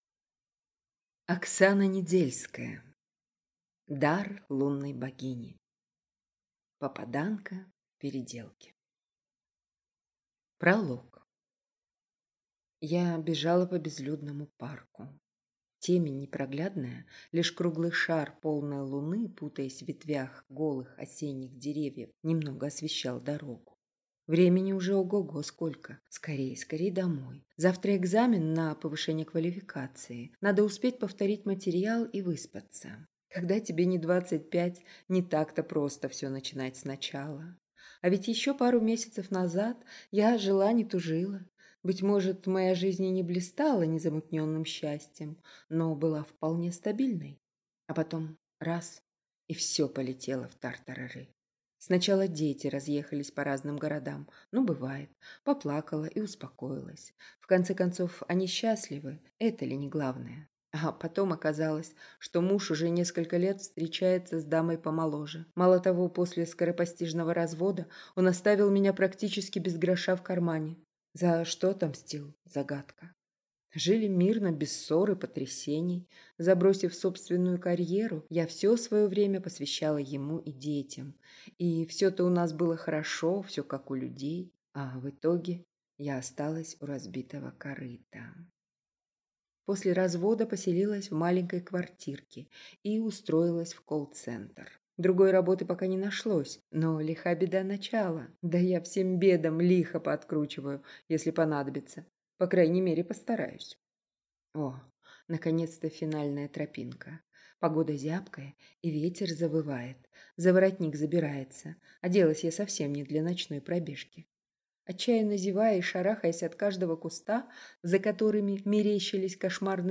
Аудиокнига Дар лунной богини. Попаданка в переделке | Библиотека аудиокниг